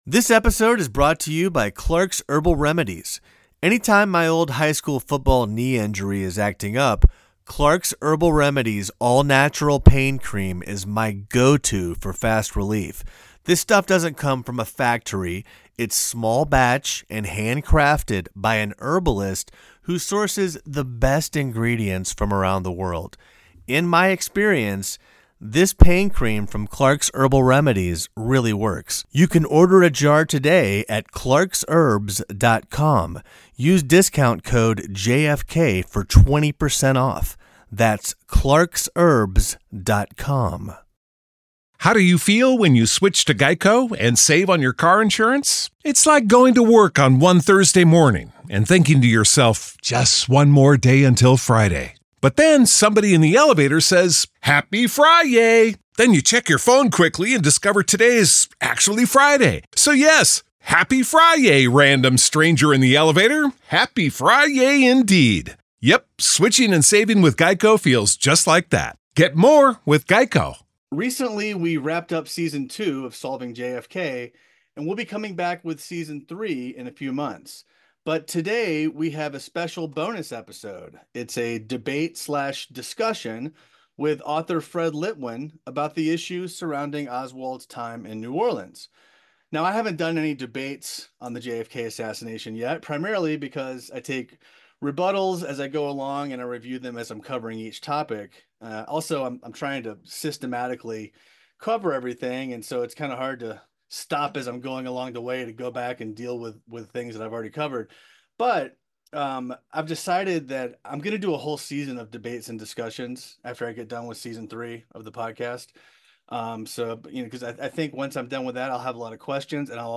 In this episode, a Warren Report critic and a lone assassin believer establish the issues where there is agreement and seek clarification about why there is disagreement on other topics.